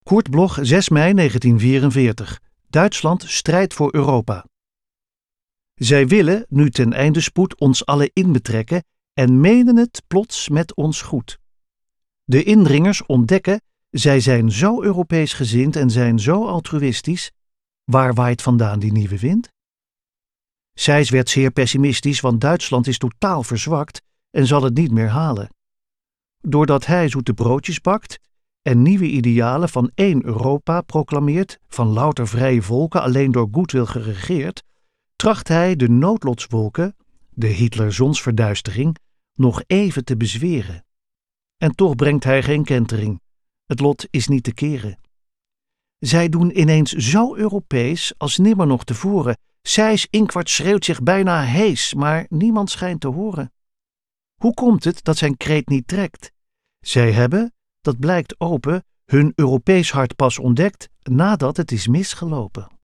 naar het gedicht
Recording: Karaktersound, Amsterdam · Editing: Kristen & Schmidt, Wiesbaden